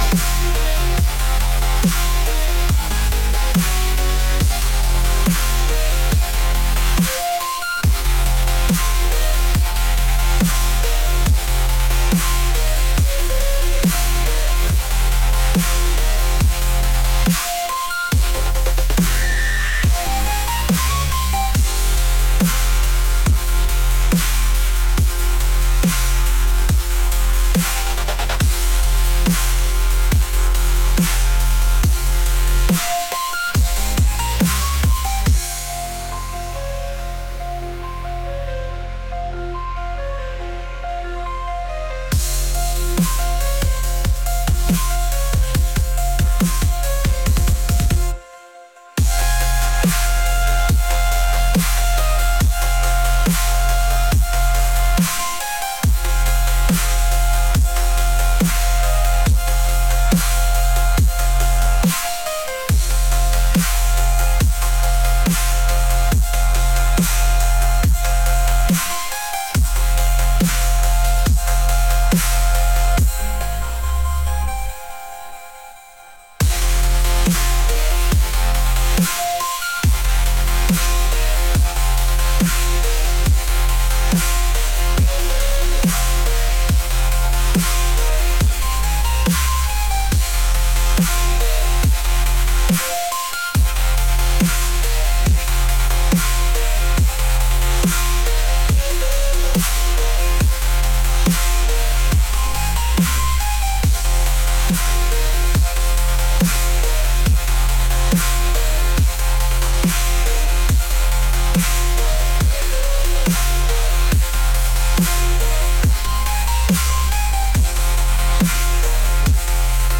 intense